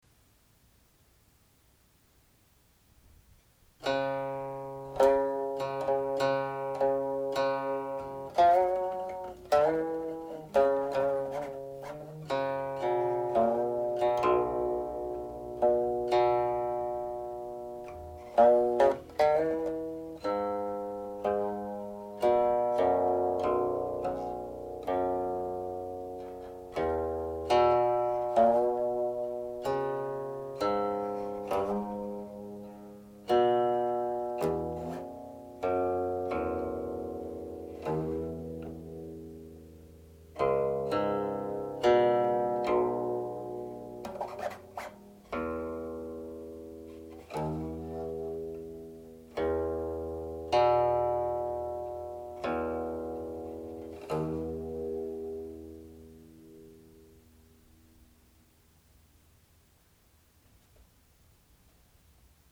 However, the modal characteristics are similar.